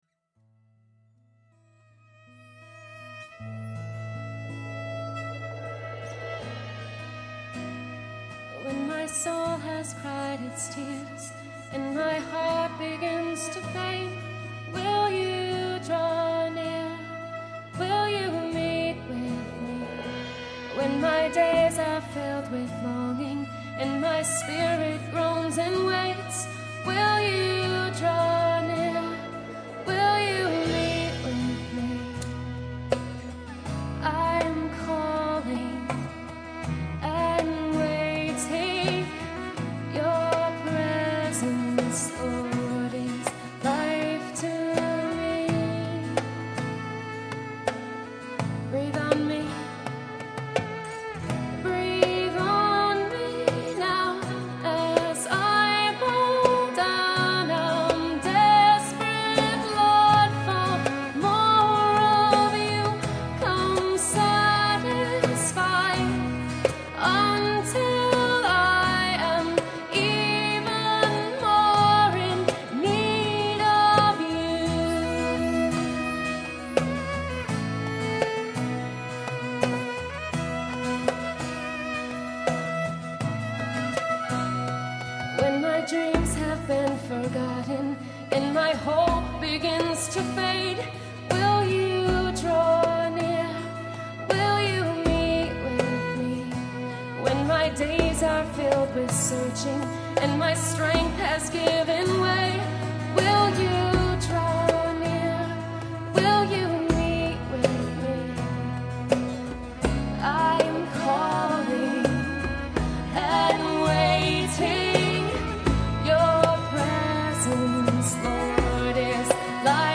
Breathe On Me Now (acoustic) - July 19, 2009
Performed live at Terra Nova - Troy on 7/19/09.